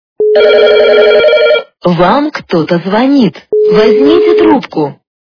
» Звуки » Смешные » Женский голос - Вам кто-то звонит, возьмите трубку!
При прослушивании Женский голос - Вам кто-то звонит, возьмите трубку! качество понижено и присутствуют гудки.
Звук Женский голос - Вам кто-то звонит, возьмите трубку!